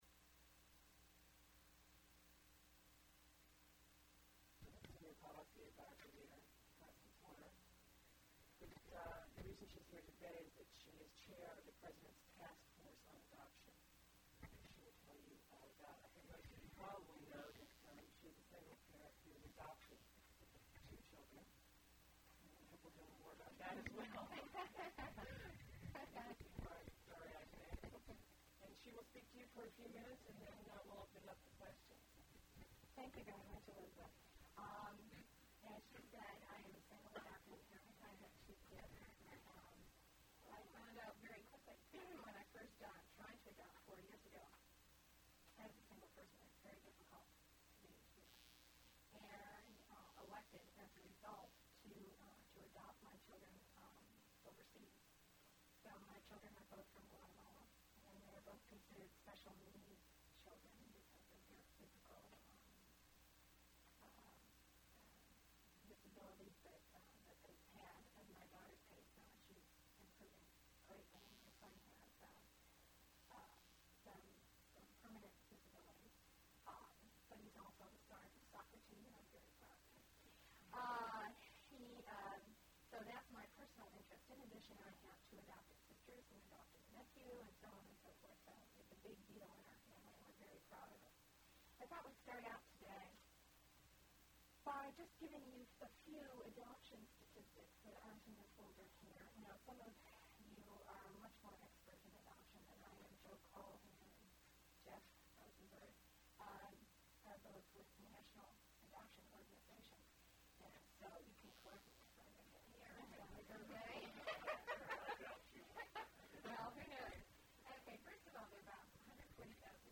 Mary Gall White House briefing on Adoption, 1/28
MP3 Audio file Tape Number WHMEDIA AUD-7G Side 1 Time Counter Reading 46:51 Personal Reference Mary Gall Collection Reference WHMEDIA Geographic Reference Washington, DC